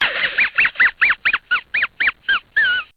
Risada do Muttley
Risada de cachorro Áudio Mutley resmungando
Categoria: Risadas
risada-do-muttley-pt-www_tiengdong_com.mp3